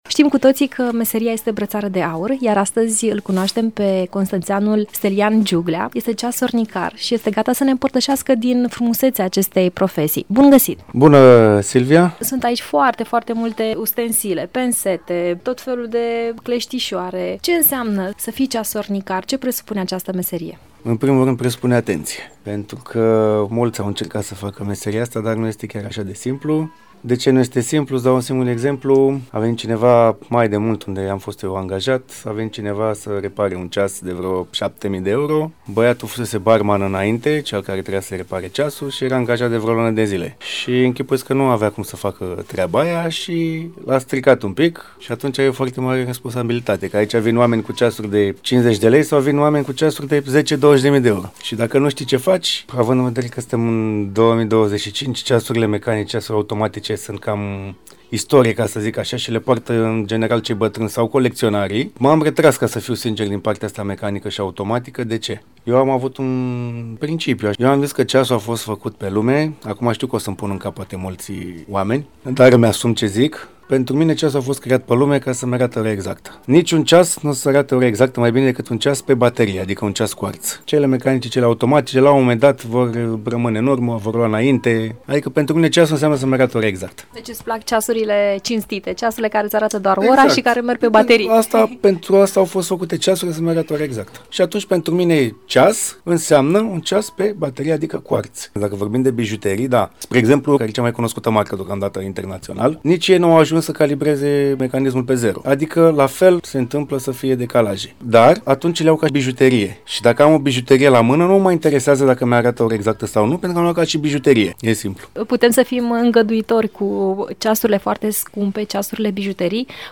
Urmează un interviu